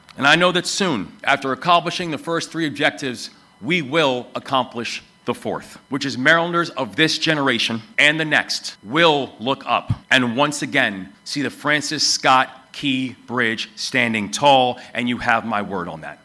Ceremony Held On Key Bridge Anniversary
State leaders gathered Wednesday morning to remember the events of March 26, 2024 when the Key Bridge in Baltimore was hit by a freighter and collapsed. Governor Wes Moore recalled the six victims on the bridge when it collapsed, the heroism of responders and recovery workers and the state and federal government’s swift action to reopen the Port of Baltimore.